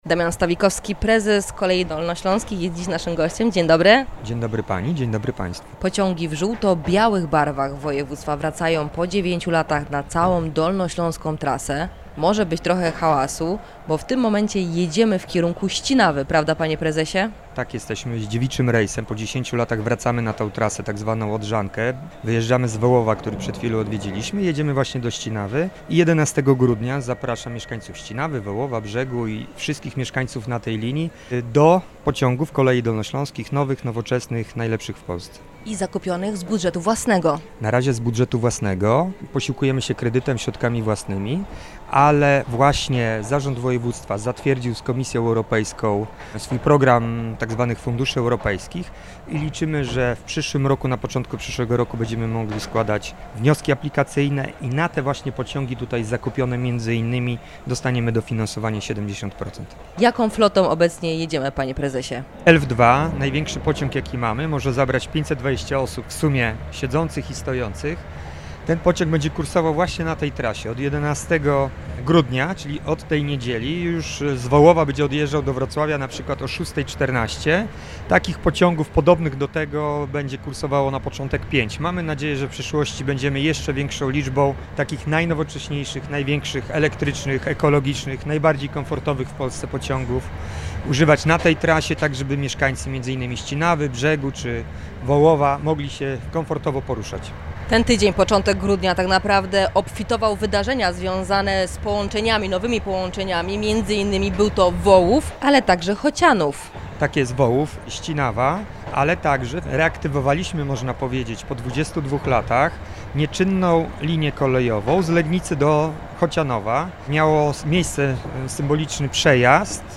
Za nami pierwszy przejazd tzw. odcinkiem Nadodrzanki, czyli trasy kolejowej z Wrocławia do Głogowa, w którym uczestniczyło Radio Rodzina. Pociągi w żółto-białych barwach województwa wracają po dziewięciu latach na całą dolnośląską trasę.